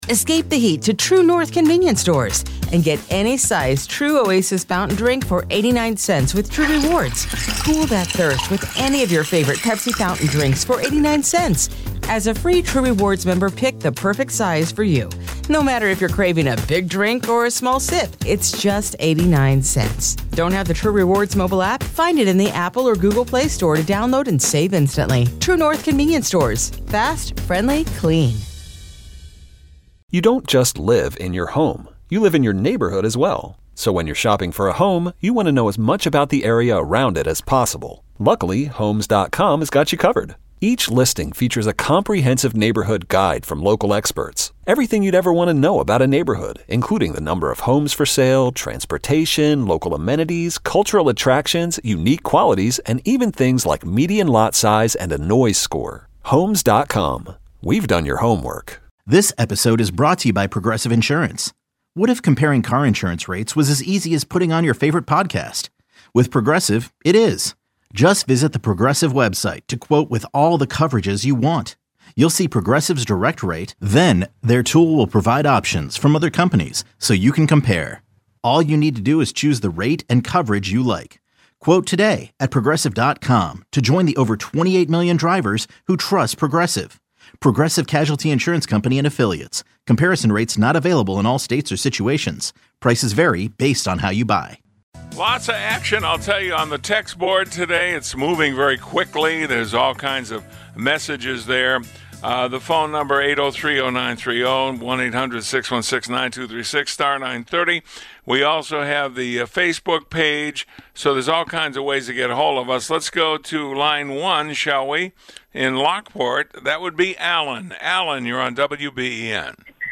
Taking your calls on what is going on in Seattle.